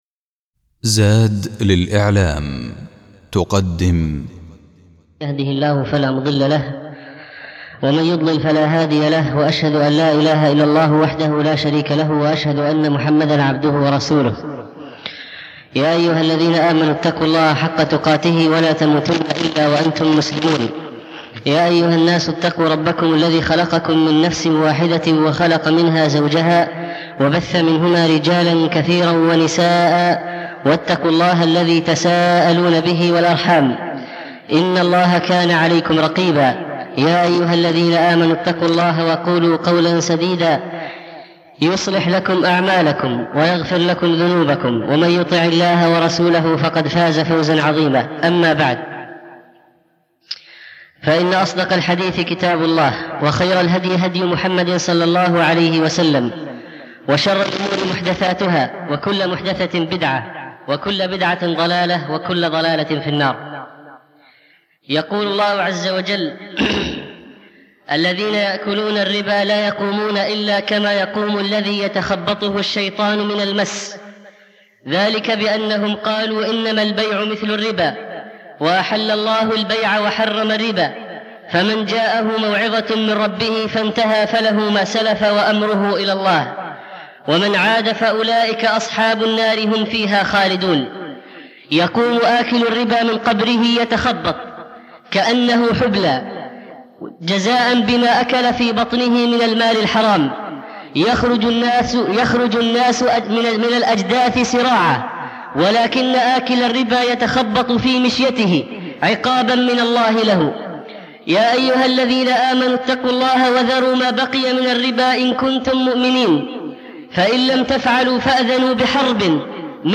الخطبة الأولى خطورة أكل الربا